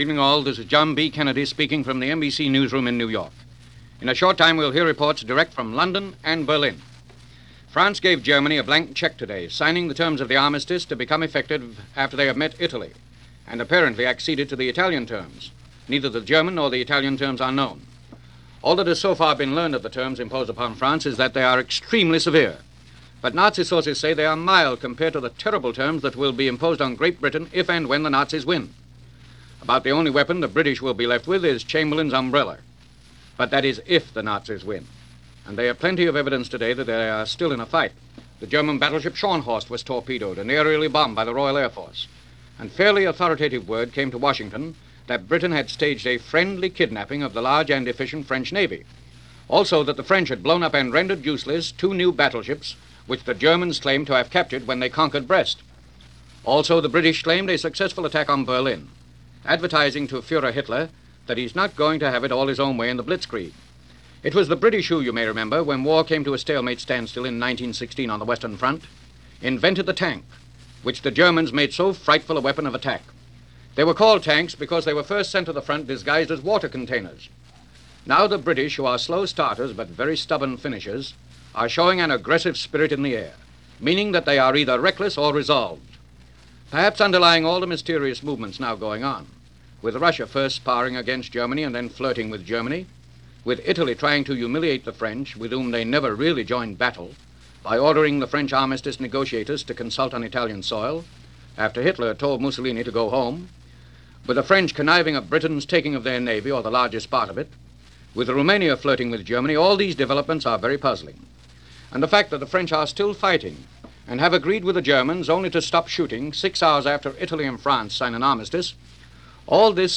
France Gives A Blank Check - Paris Is Occupied - America Feels The Pressure - June 22, 1940 - News from NBC.